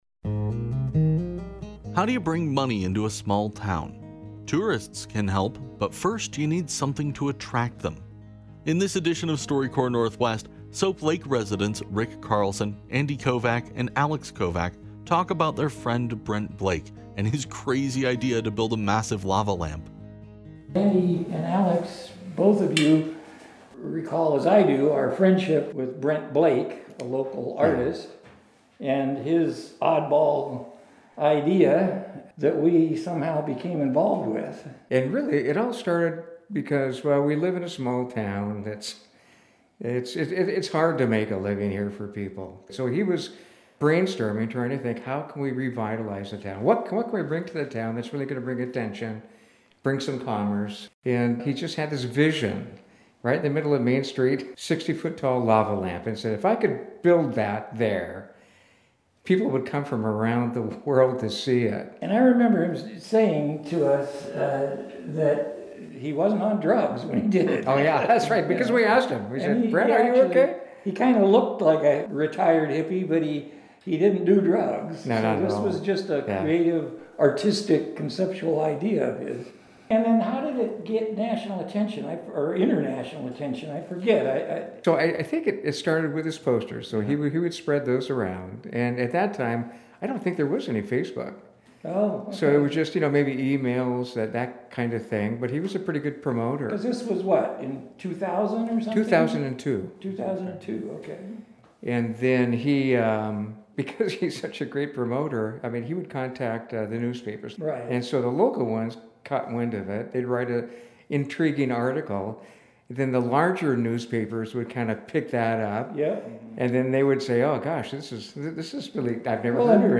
This episode of StoryCorps Northwest was recorded in Soap Lake as part of Northwest Public Broadcasting’s centennial celebration.